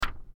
paper.mp3